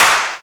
VEC3 Claps 096.wav